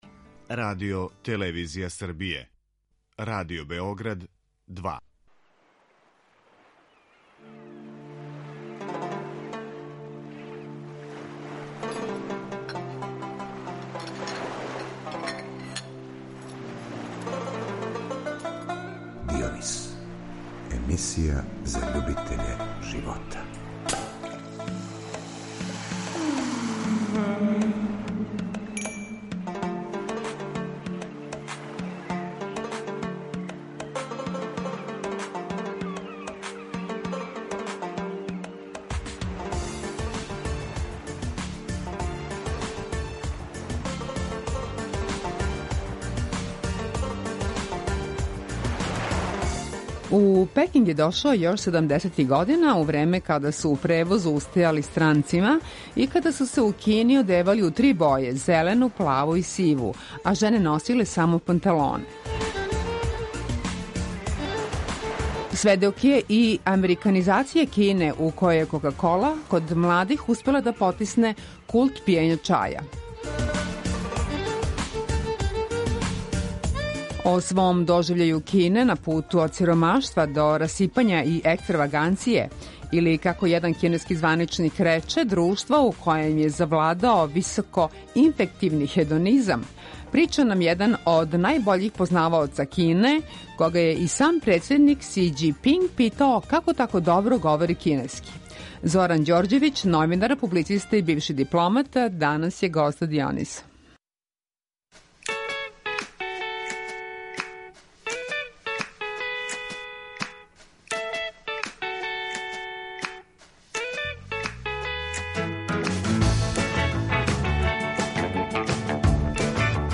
гост је у Дионису.